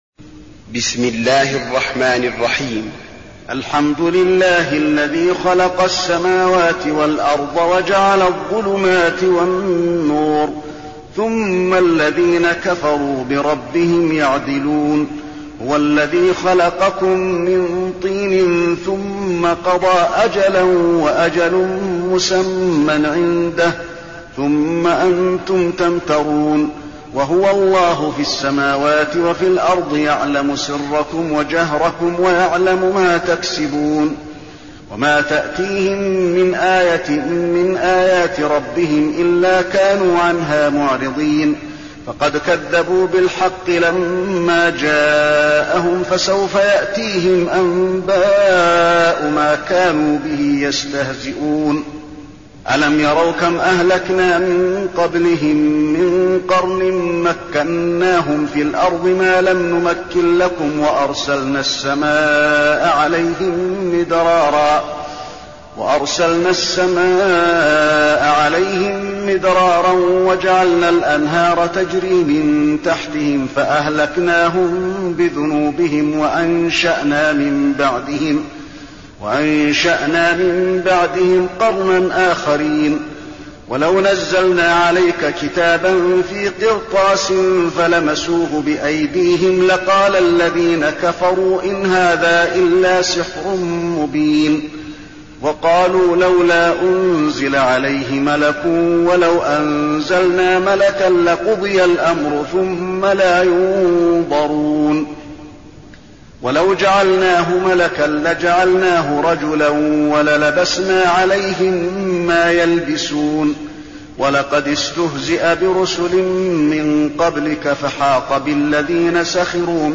المكان: المسجد النبوي الأنعام